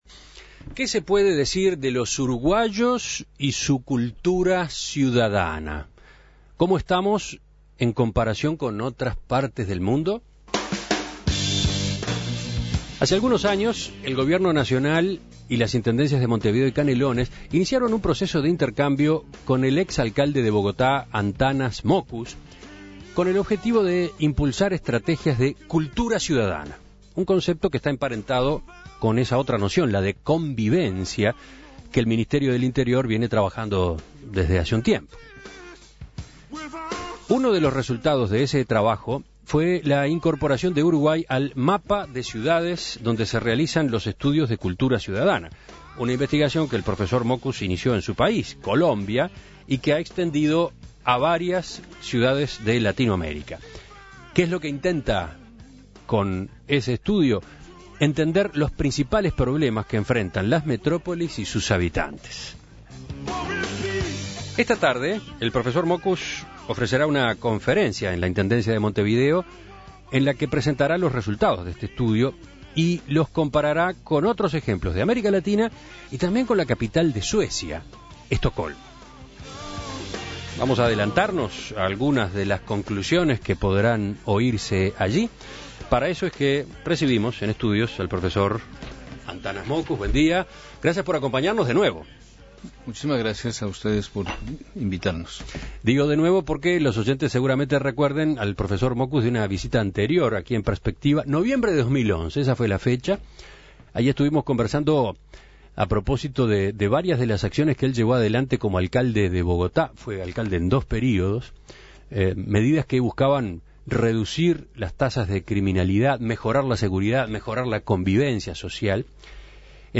Previo a la presentación de los resultados en la capital, Mockus fue entrevistado por En Perspectiva.